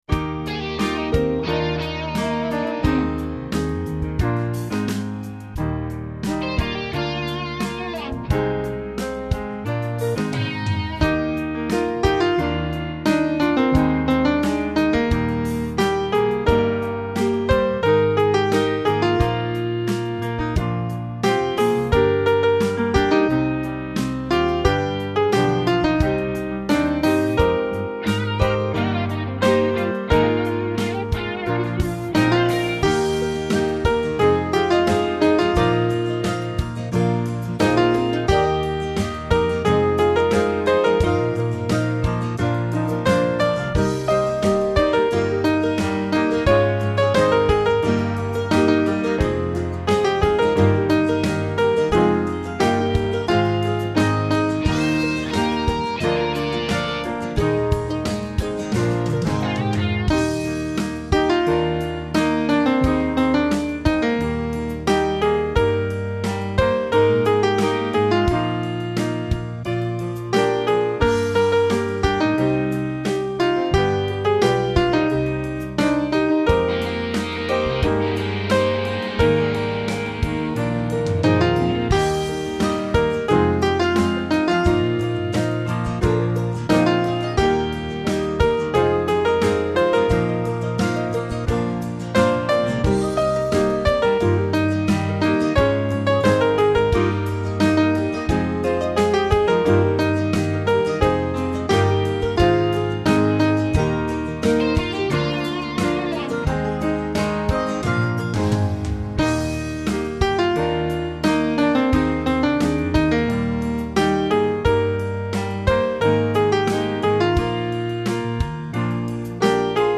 Oddly, however, the chorus is harder to sing than the verses with a high E and some rhythmic interest that would require some work.
This parish does a terrific version including the section with just handclaps for accompaniment but even they don’t get the “spread the goodness of God” bit in the chorus right.